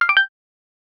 Audio-feedback Sound Effects - Free AI Generator & Downloads
game-sound-effect-for-col-kwbbr7oq.wav